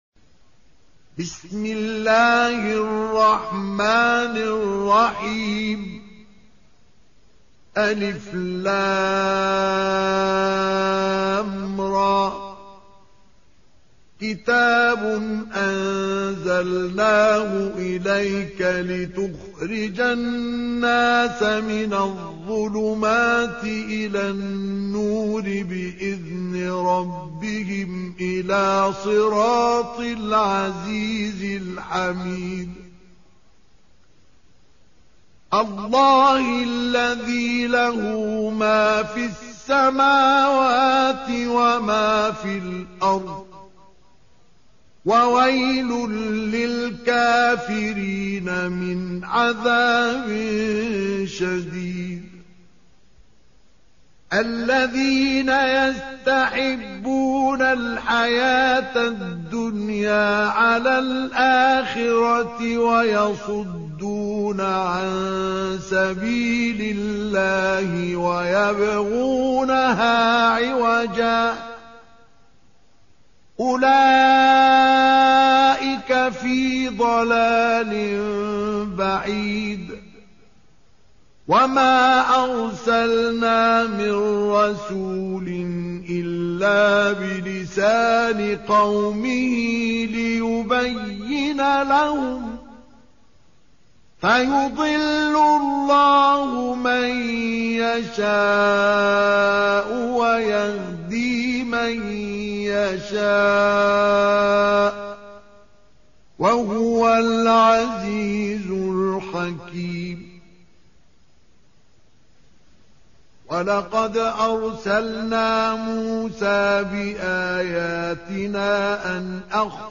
14. Surah Ibrah�m سورة إبراهيم Audio Quran Tarteel Recitation
Surah Sequence تتابع السورة Download Surah حمّل السورة Reciting Murattalah Audio for 14.